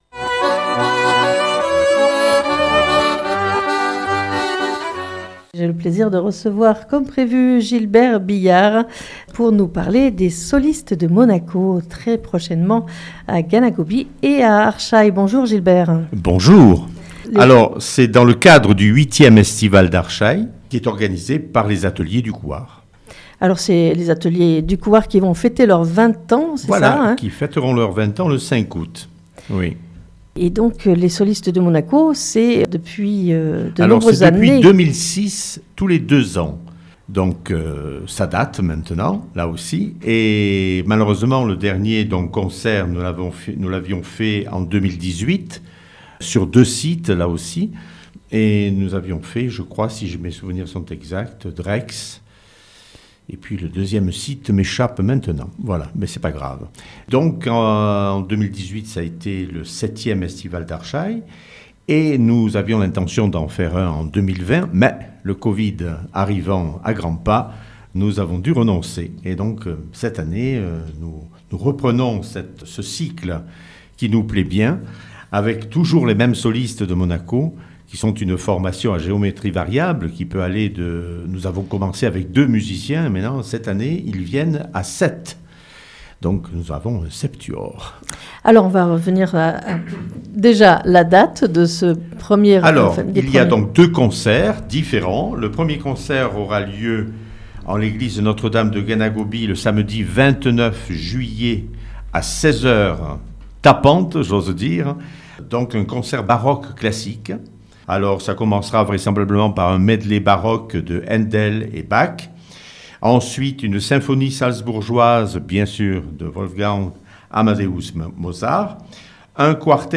sur Fréquence Mistral Digne 99.3